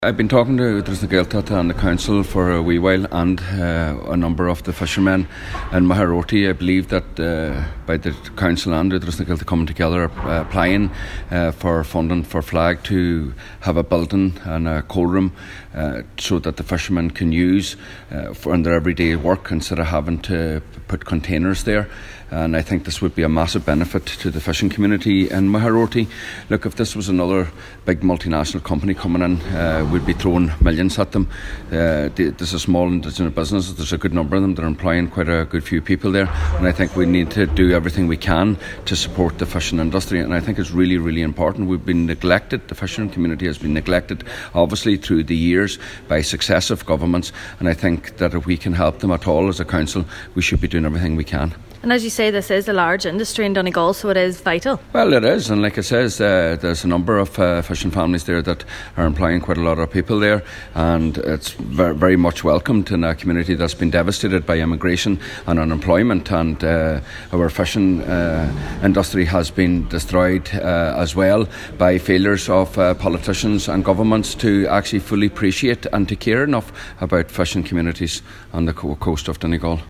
Glenties Councillor Micheal Cholm MacGiolla Easbuig is this year calling on funding to be secured for the provision of a building and cold room at Mageraroarty pier for use by local fishermen.